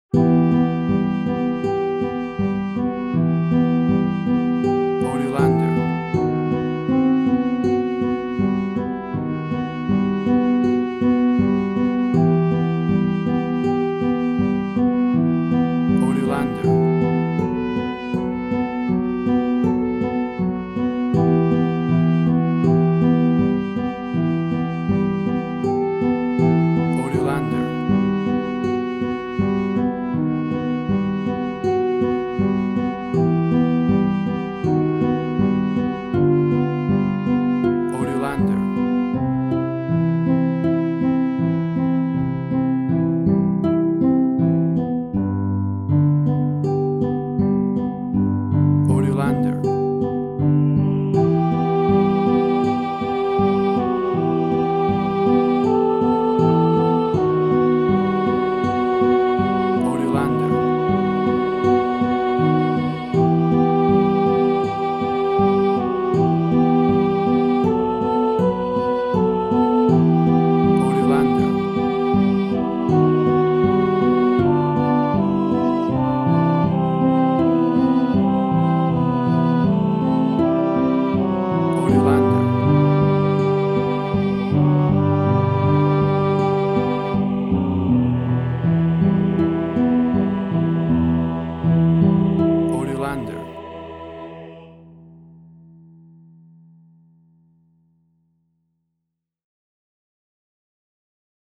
Latin Fusion Orchestral Hybrid.
Tempo (BPM): 80